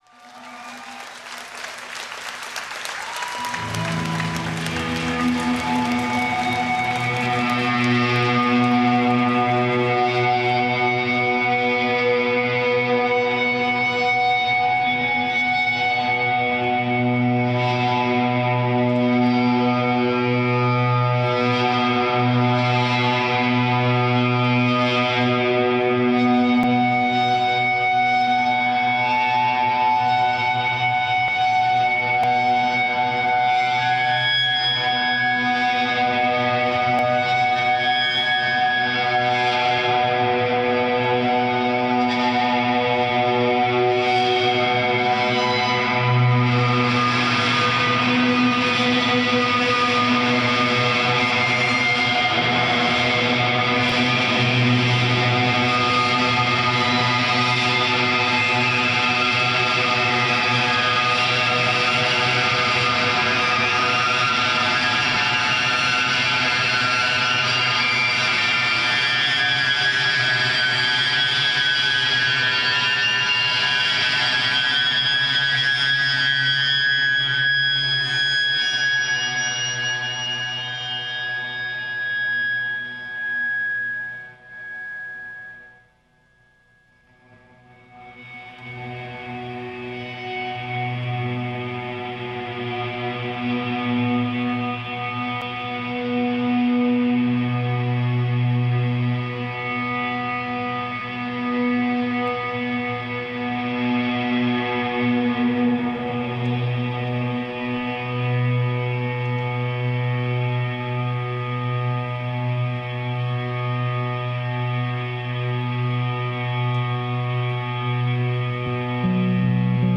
recorded at The Theatre Of The Living Arts, Philadelphia
guitar and vocals
bass
drums
serene, sublime and ethereal.